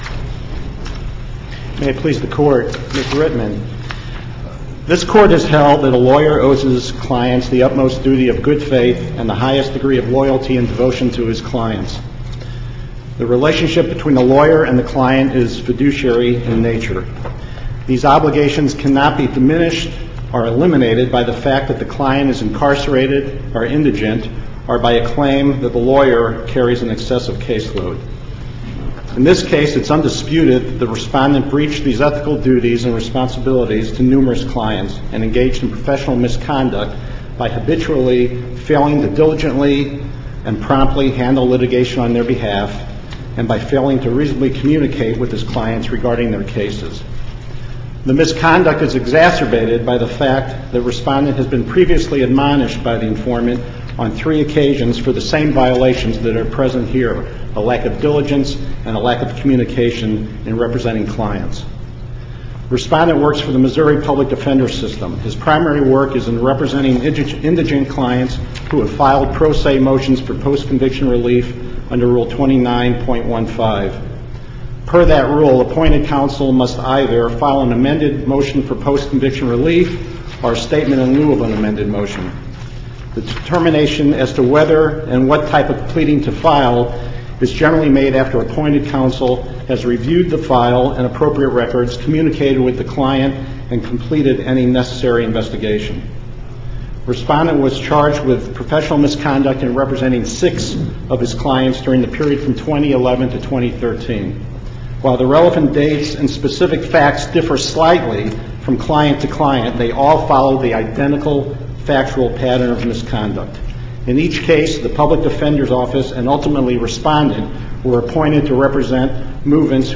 MP3 audio file of arguments in SC96376